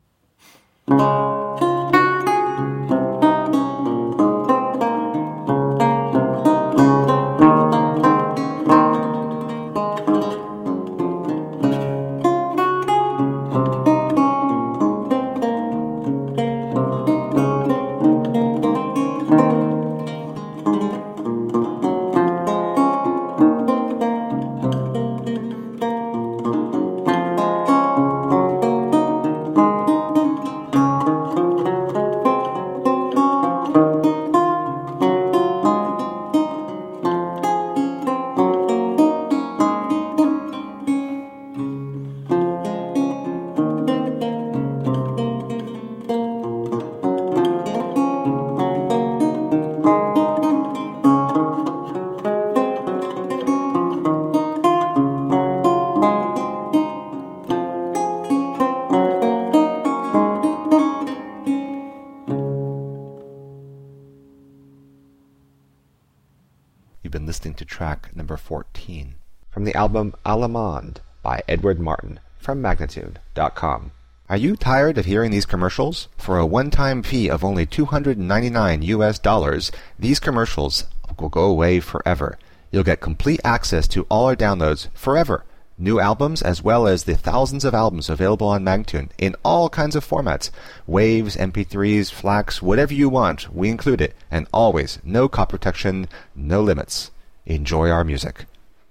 Vihuela, renaissance and baroque lute
Classical, Baroque, Renaissance, Instrumental
Lute